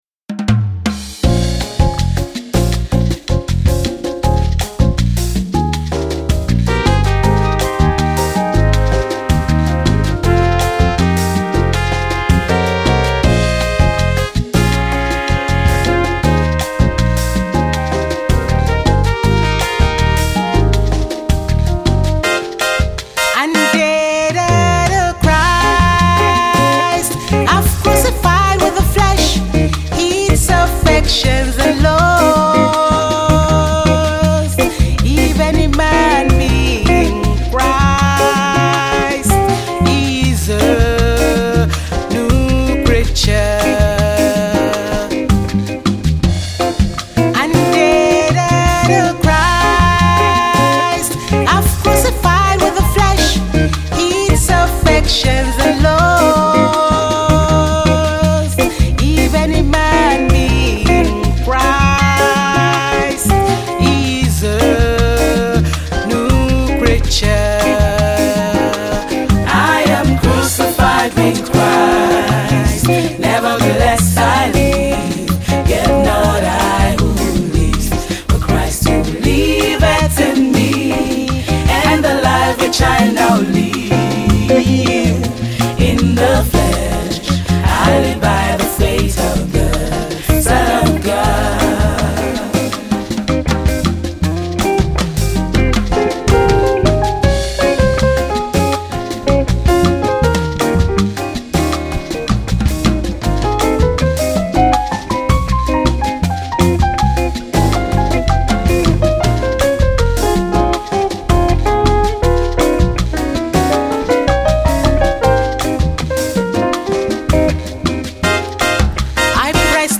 a Nigerian Born UK based gospel artiste